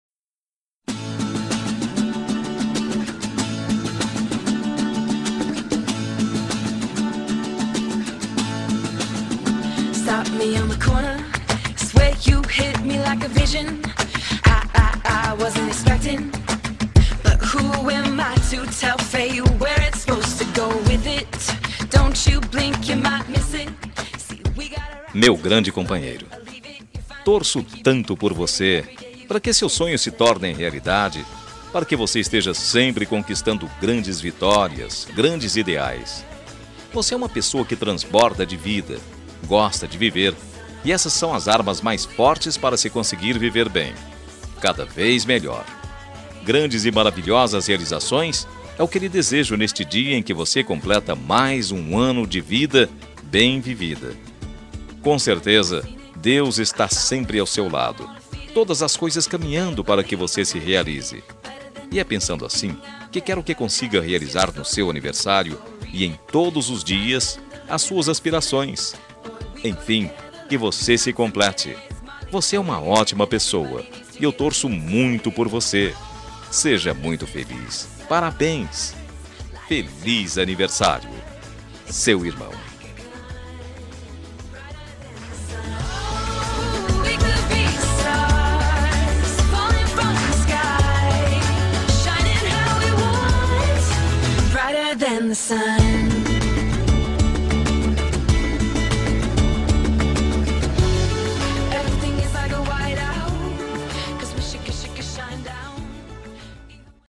Telemensagem de Aniversário de Irmão – Voz Masculino – Cód: 202234